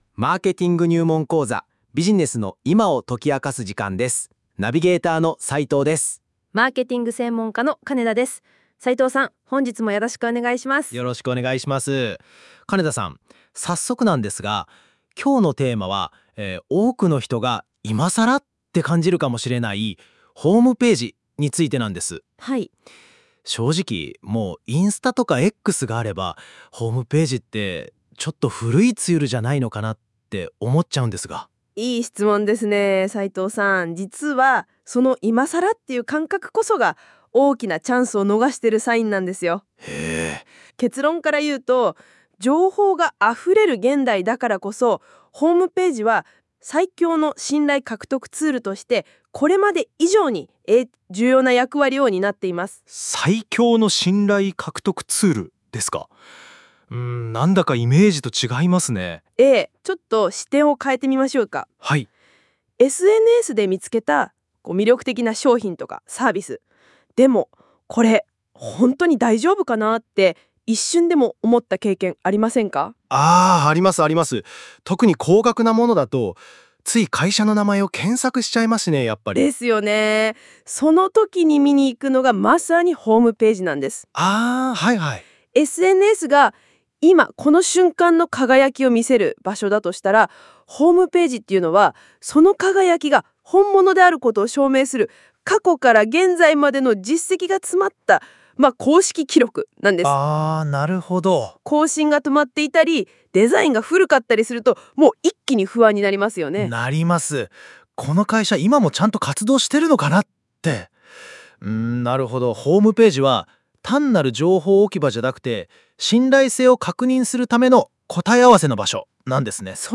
会話の文字起こしはこちら↓